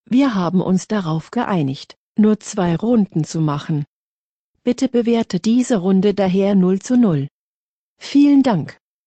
Googleübersetzer hat eine sexy stimme.